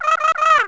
BIRD09.WAV